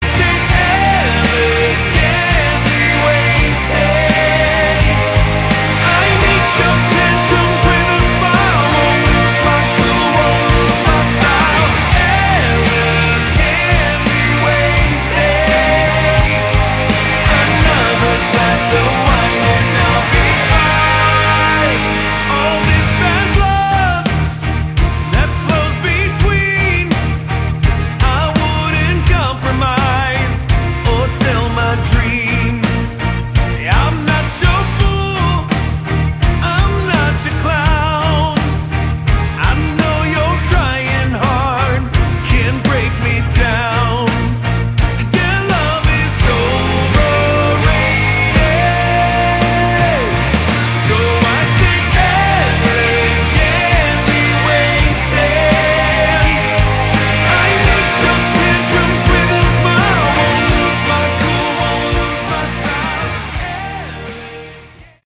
Category: Hard Rock
guitar, bass, keyboards, vocals